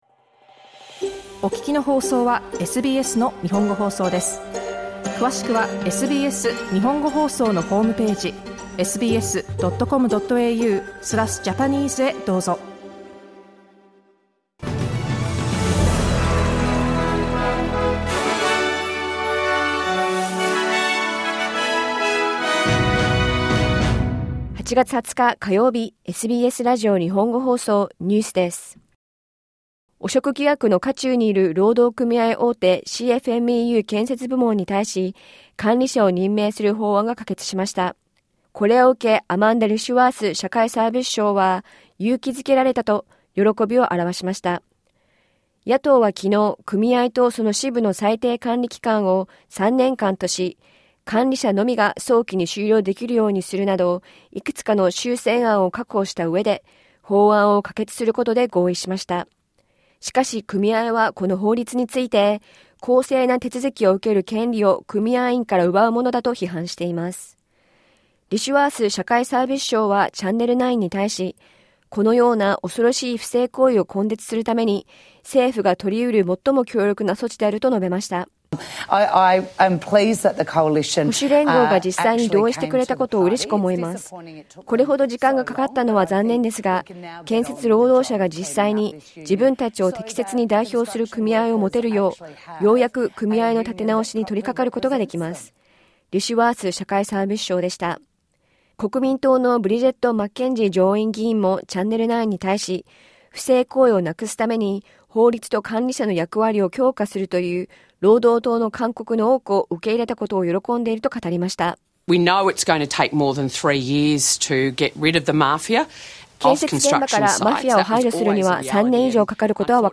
汚職疑惑の渦中にいる労働組合大手CFMEU建設部門に対し、管理者を任命する法案が可決されました。午後1時から放送されたラジオ番組のニュース部分をお届けします。